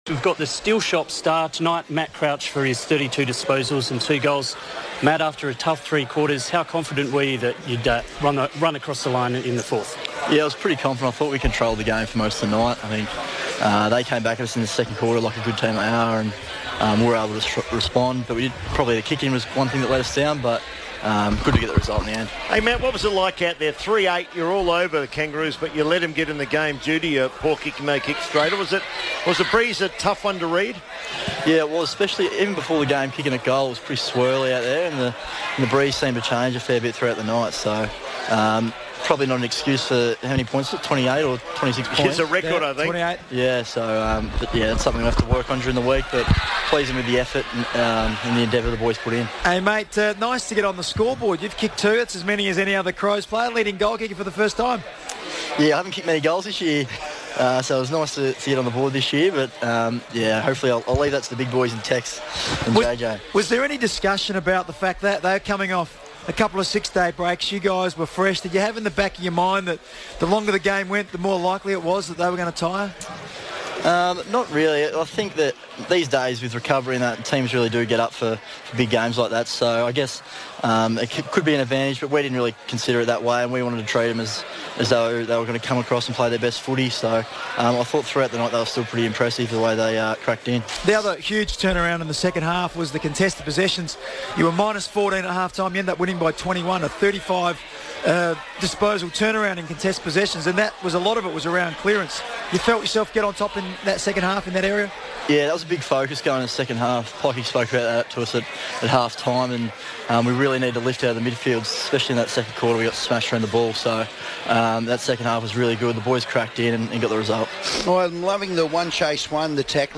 Emerging midfielder Matt Crouch spoke on FIVEaa radio after earning 32 possessions and slotting two goals against North Melbourne.